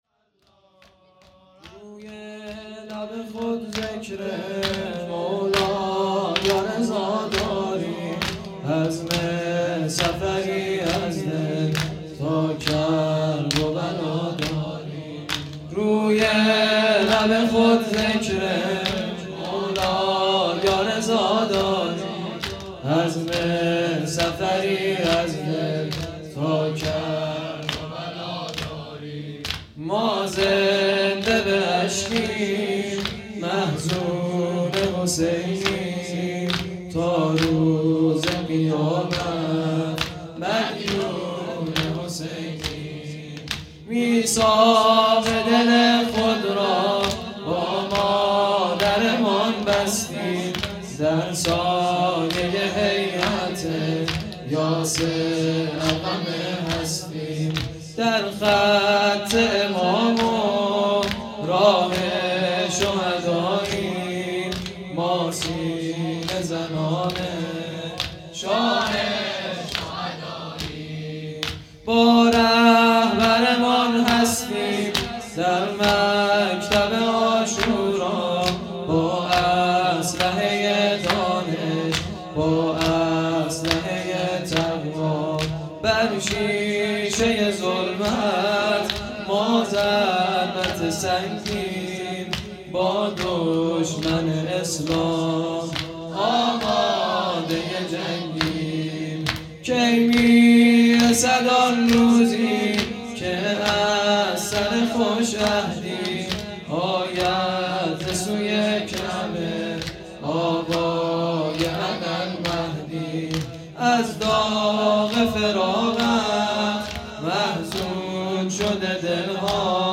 هيأت یاس علقمه سلام الله علیها
شور
شب هفتم محرم الحرام 1441